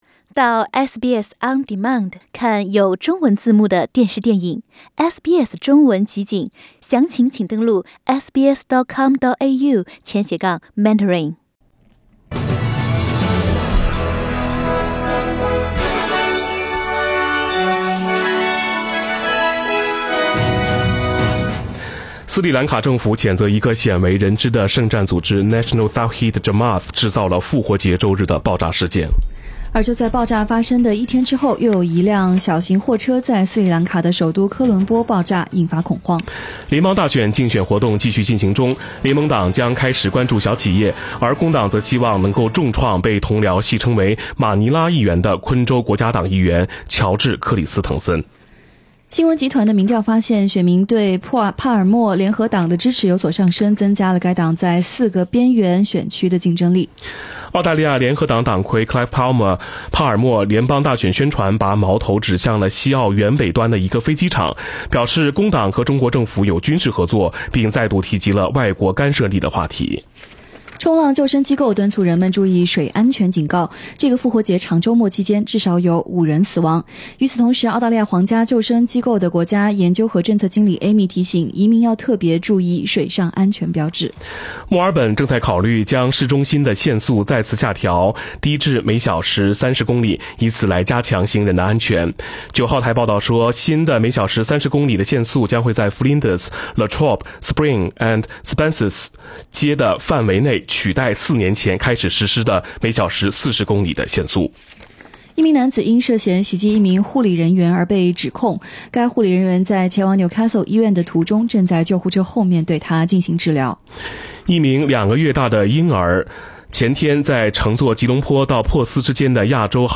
SBS早新闻（4月23日）